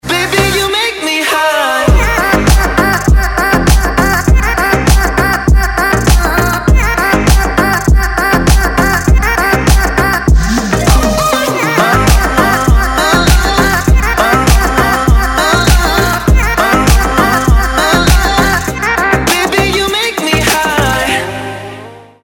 • Качество: 192, Stereo
ритмичные
мужской вокал
dance
club
electro